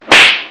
slap_C3VW76f.mp3